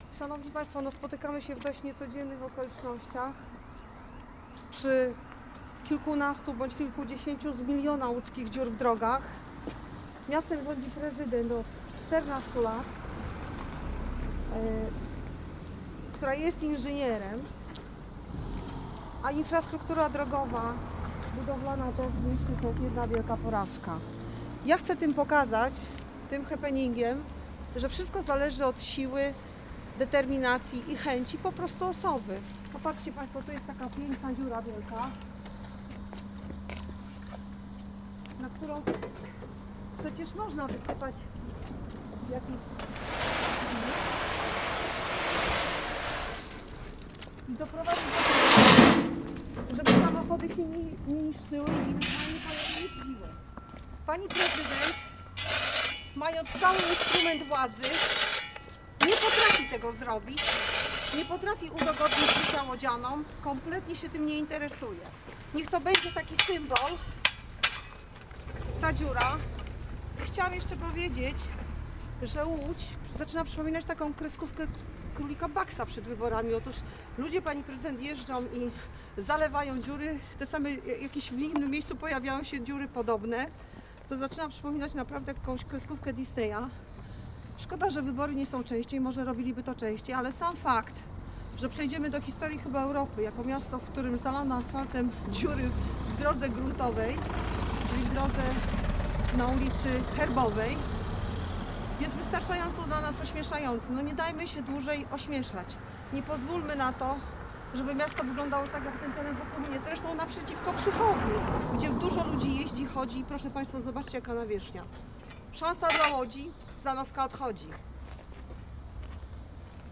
Posłuchaj zapisu całej konferencji: